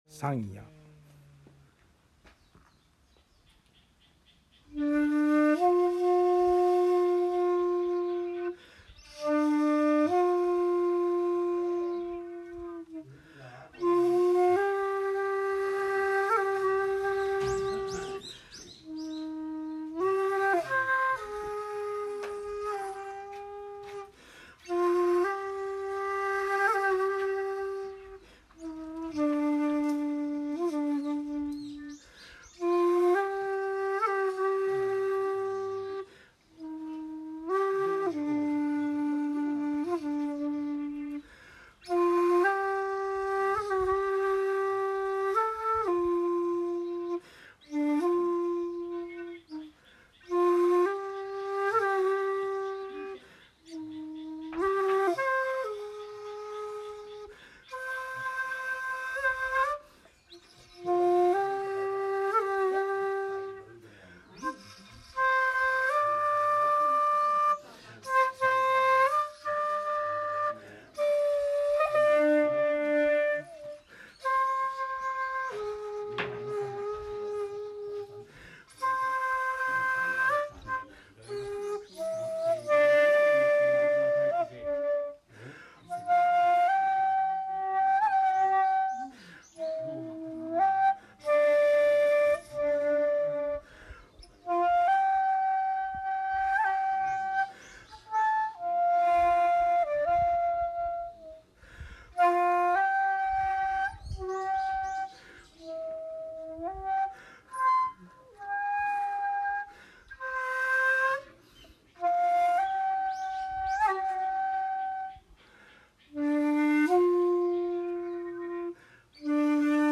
（尺八音源：「三谷」抜粋） ♪♪
(写真①　延命寺で尺八吹奏)